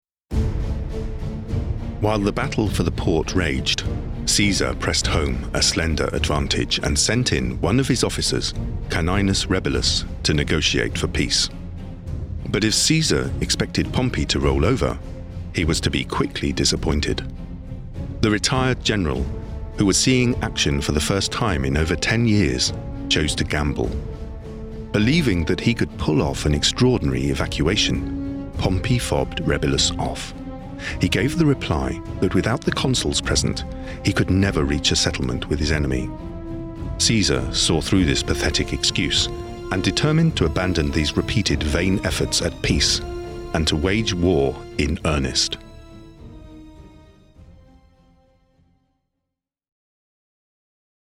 Narration
I have a warm and engaging English RP accent which is suitable for a wide range of projects, including audiobook, corporate and commercial.
I produce audio from my purpose built home studio where I use a Shure SM7B mic with a Focusrite Scarlet 2i4 interface and Logic Pro on a Mac.
BaritoneBass